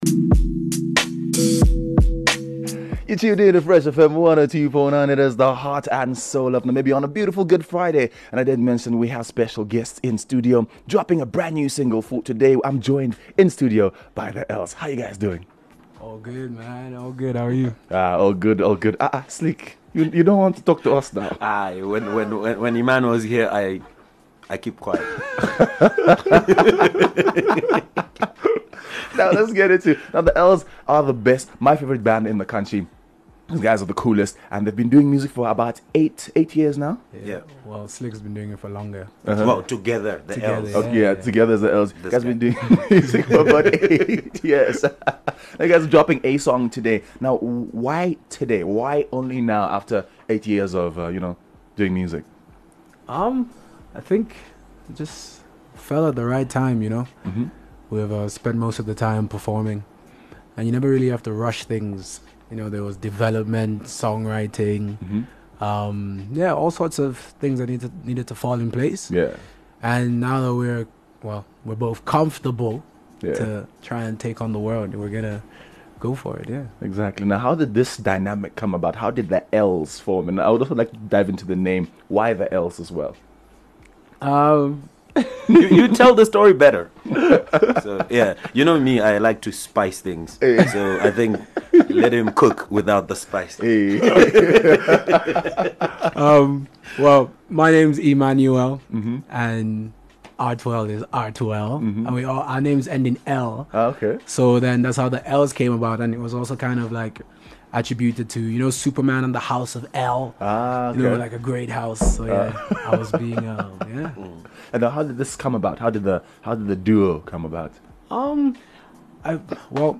Local Duo
in studio